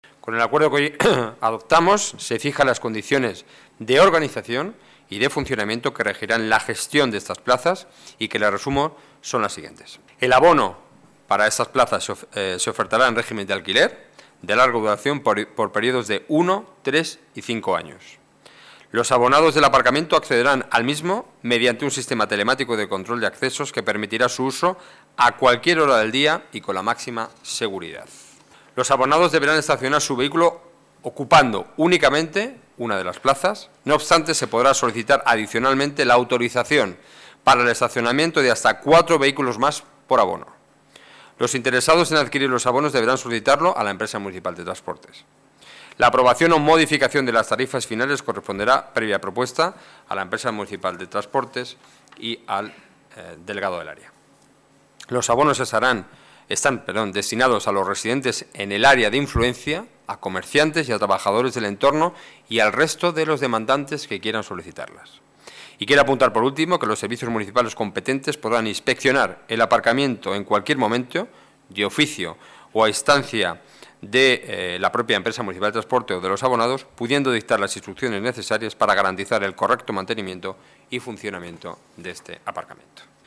Nueva ventana:Declaraciones del portavoz del Gobierno municipal, Enrique Nuñez: Junta de Gobierno aparcamiento Barceló